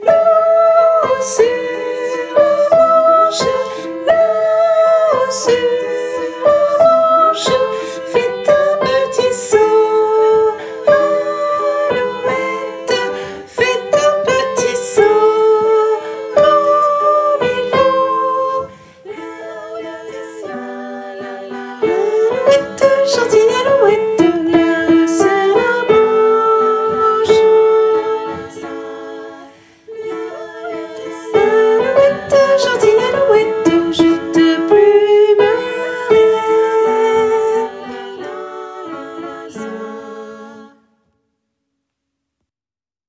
- Œuvre pour choeur à 4 voix mixtes (SATB)
- chanson populaire de Lorraine
MP3 versions chantées
Tenor et autres voix en arrière-plan